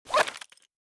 pistol_draw.ogg